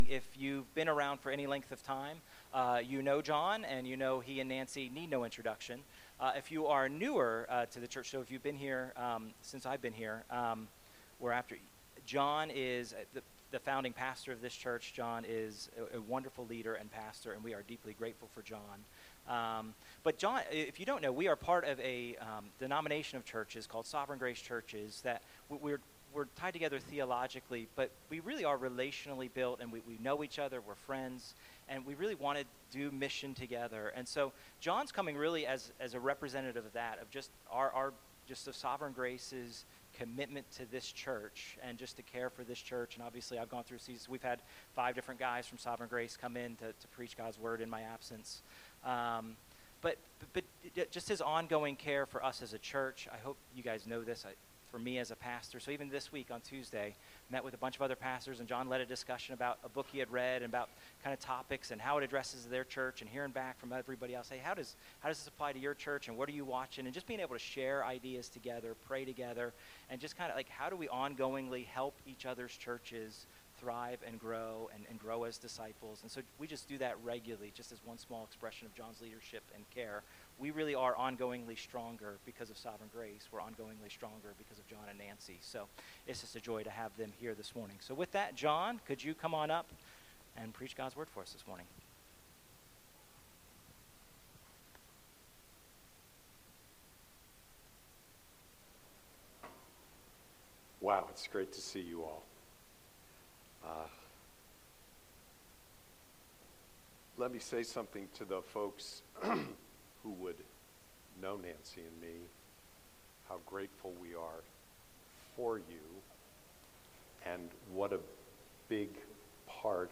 » Sermons